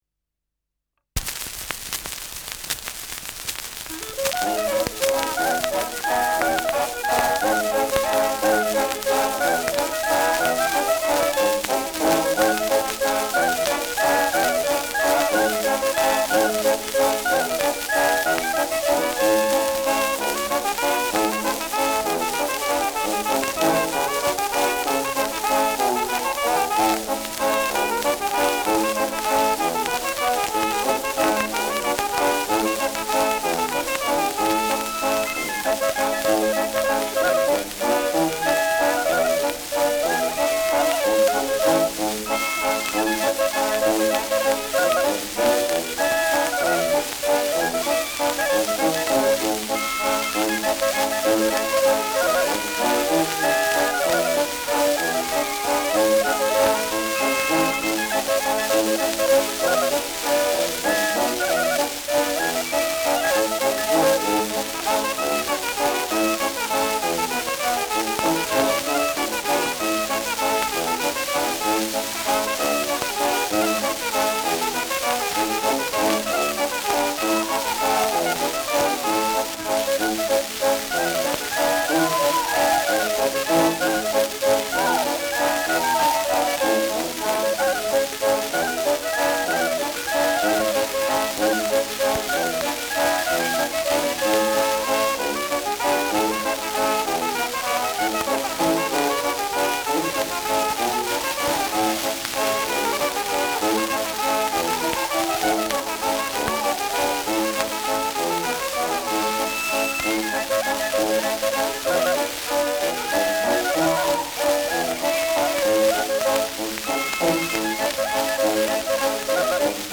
Schellackplatte
Tonrille: Abrieb : leichte Kratzer durchgängig
ausgeprägtes Rauschen : Knistern